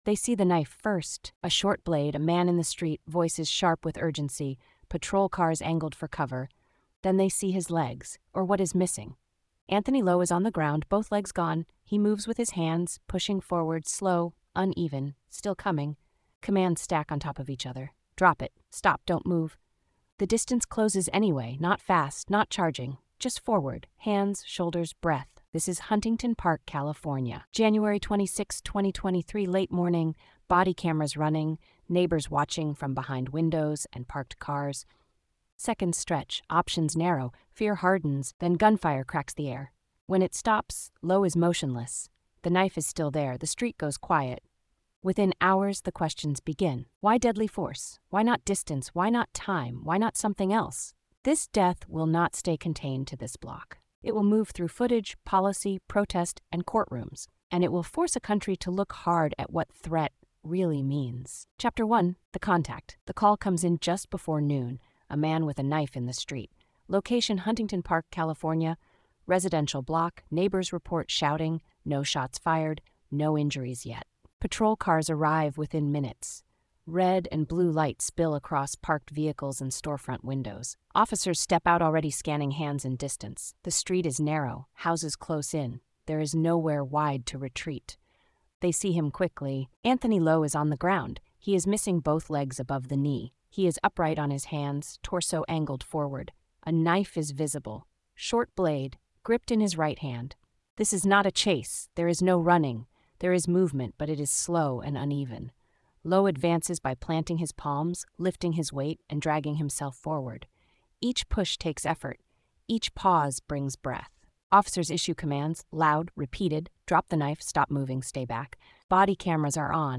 Told in a neutral, gritty documentary style, the story reconstructs the incident from first contact through official findings, separating confirmed facts from public interpretation. It focuses on pressure, distance, training doctrine, disability, and the limits of split-second decision making.